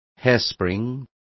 Also find out how espiral is pronounced correctly.